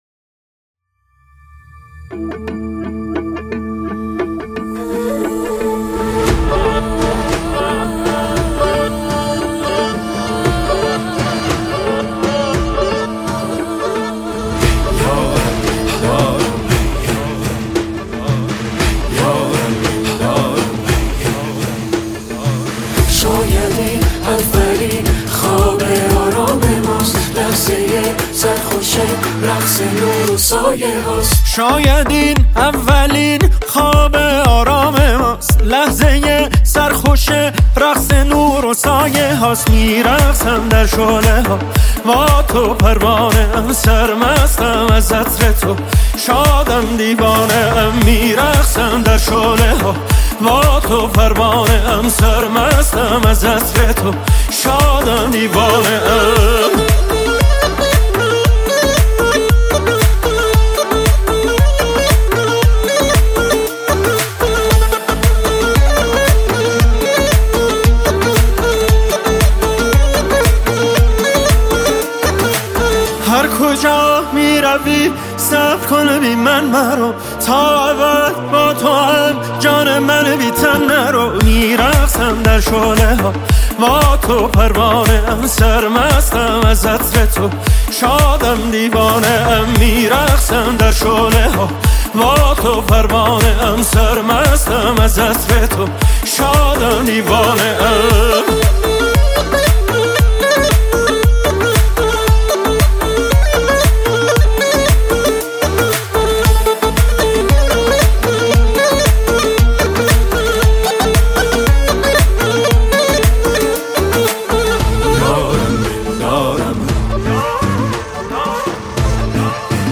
نی‌انبان